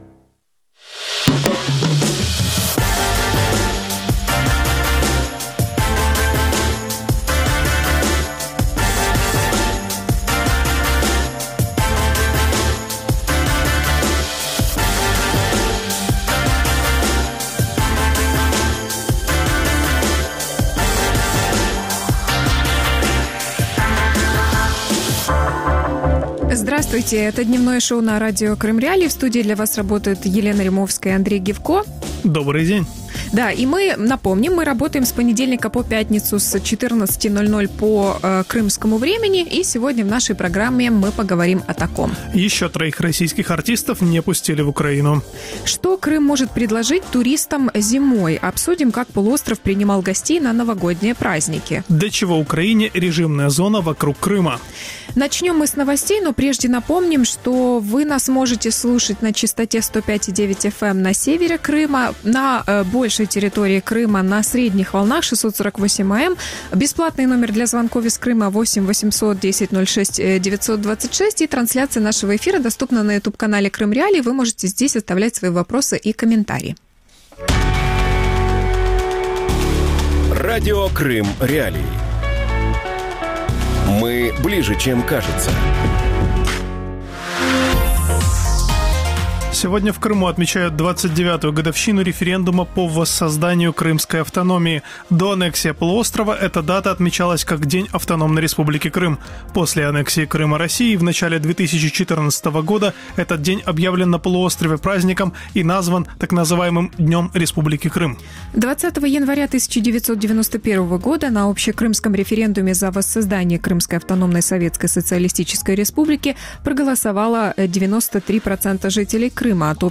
Зимний туризм в Крыму | Дневное ток-шоу